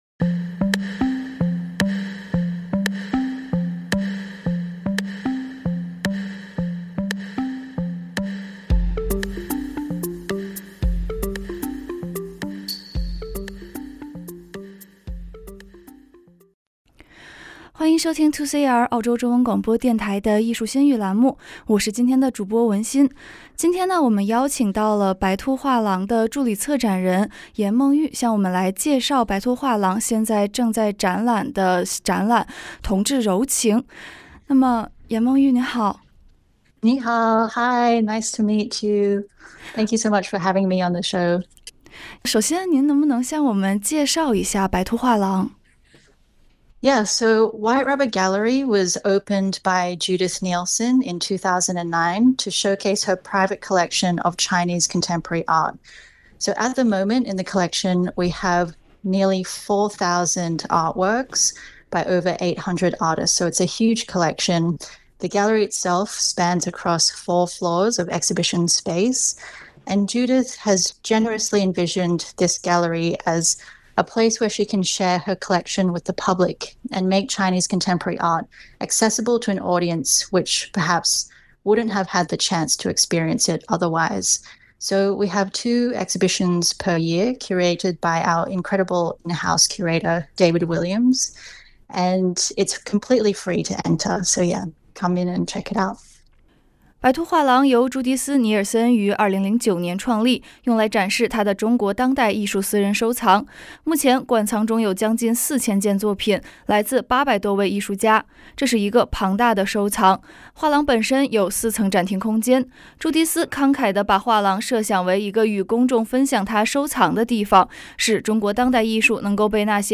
访问录音：
藝術馨語-9-访问白兔美术馆的工作人员-1.mp3